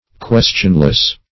Questionless \Ques"tion*less\, a.